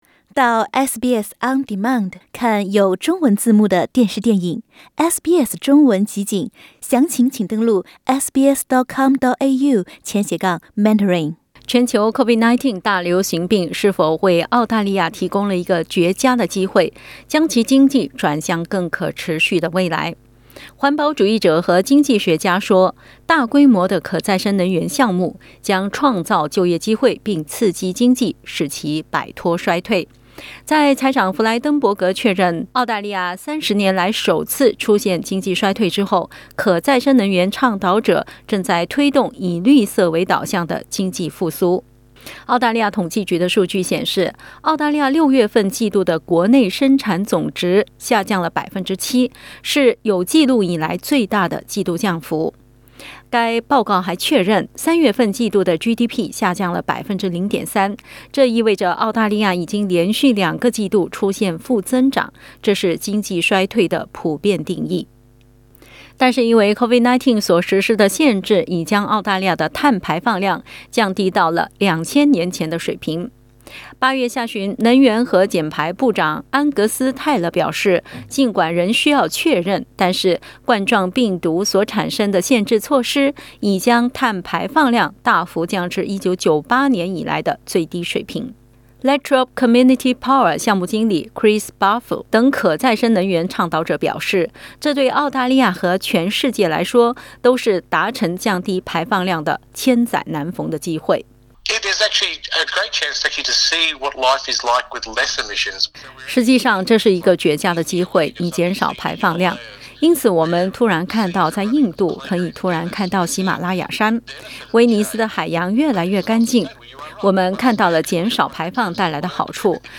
环保主义者和经济学家说，大规模的可再生能源项目将创造就业机会并刺激经济，使澳洲摆脱衰退。 点击图片收听详细报道。